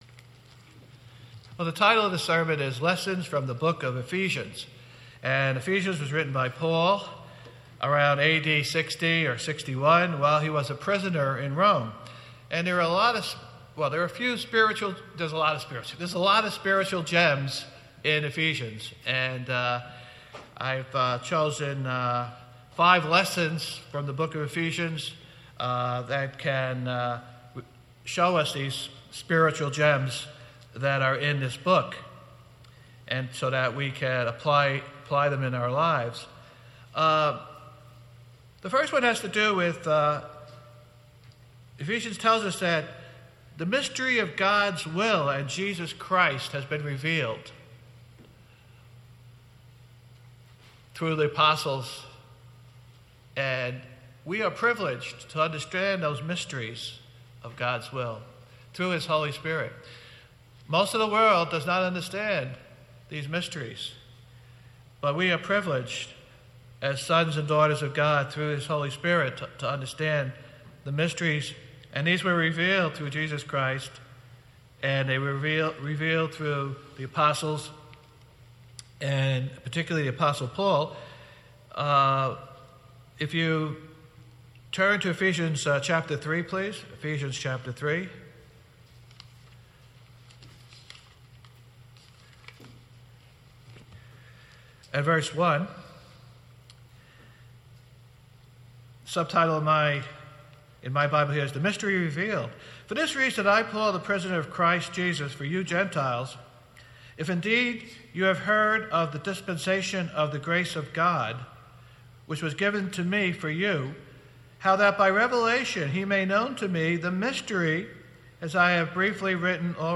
The book of Ephesians is filled with spiritual gems. This sermon goes through some of the lessons we can learn by studying these spiritual gems.